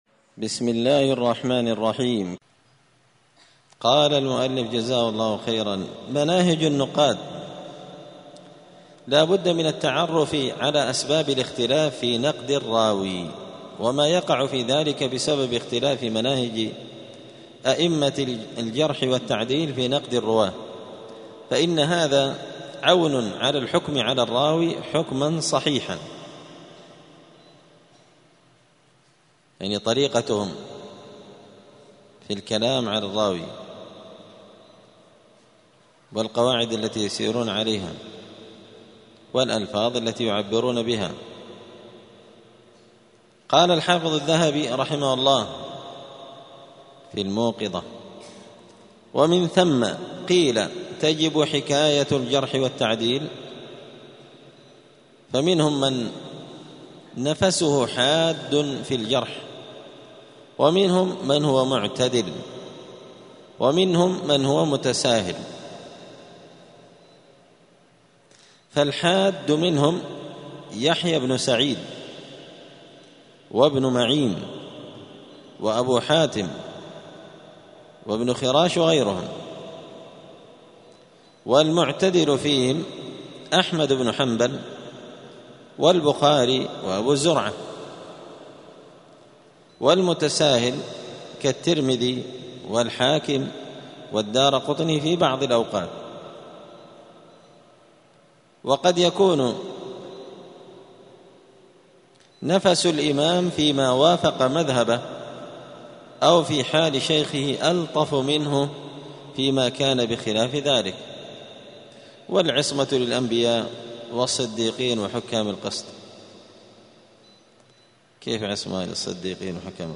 *الدرس الثالث والستون (63) باب مناهج النقاد.*